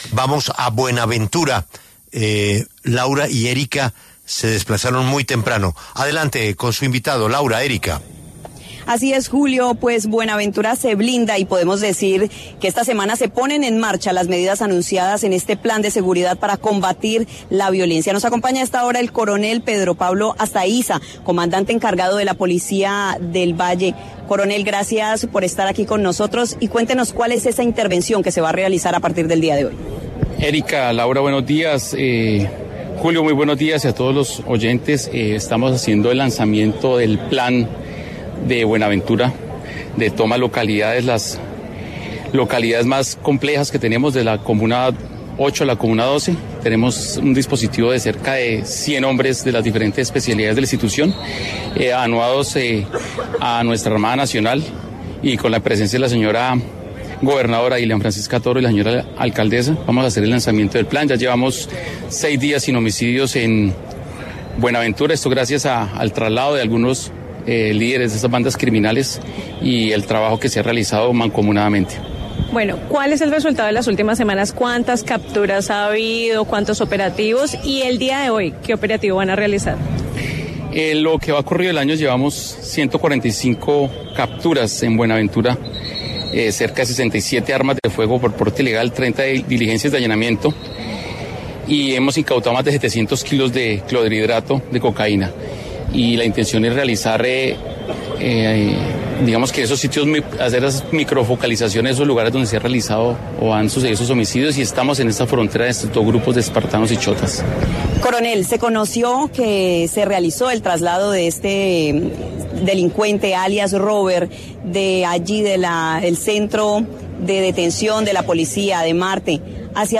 El coronel Pedro Pablo Astaiza Cerón, comandante (e) de la Policía de Valle del Cauca, habló en La W sobre la situación de orden público que se vive en Buenaventura.